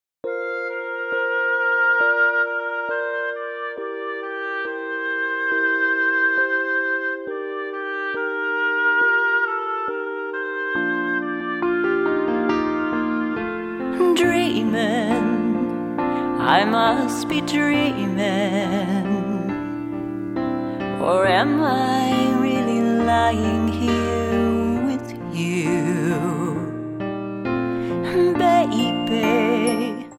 Tonart:Bb-C Multifile (kein Sofortdownload.
Die besten Playbacks Instrumentals und Karaoke Versionen .